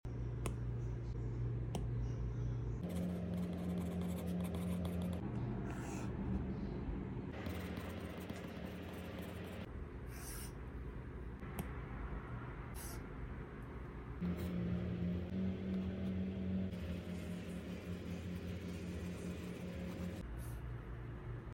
iPad note taking ASMR SOUND ON🔊 sound effects free download